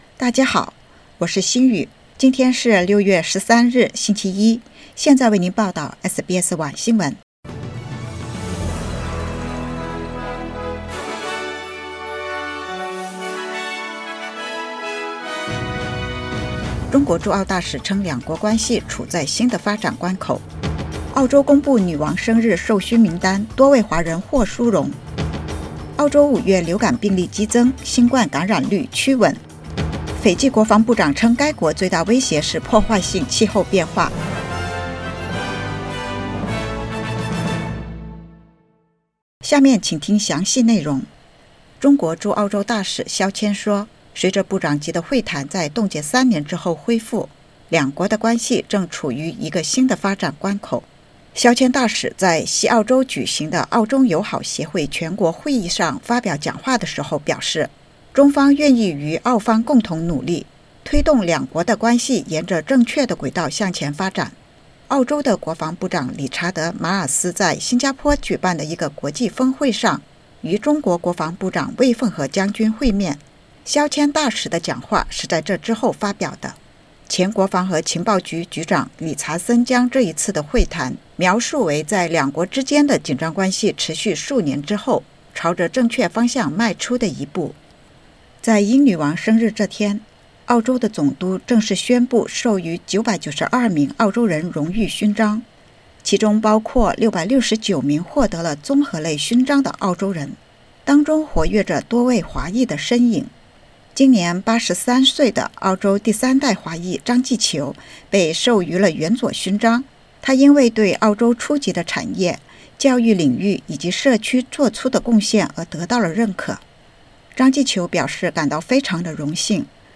SBS晚新闻（2022年6月13日）